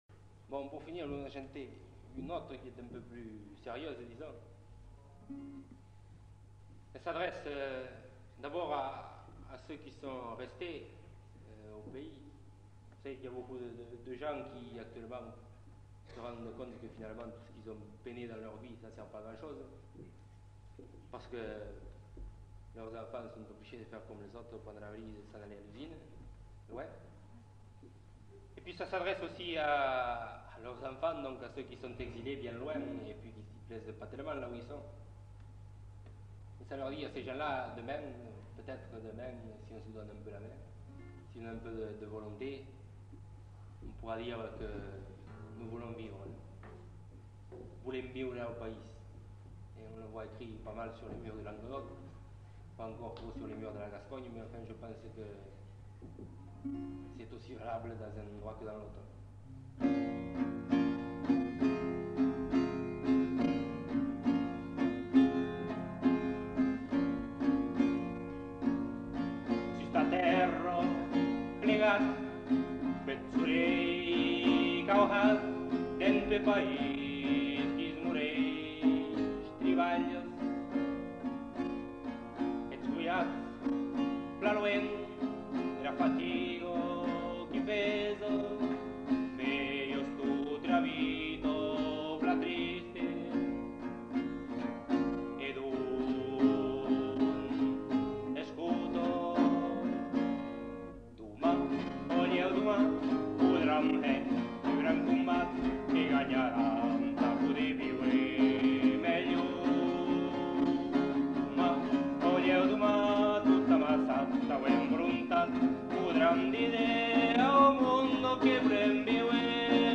Lieu : [sans lieu] ; Gers
Genre : chanson-musique
Effectif : 1
Type de voix : voix d'homme
Production du son : chanté
Instrument de musique : guitare
Commentaire de l'interprète.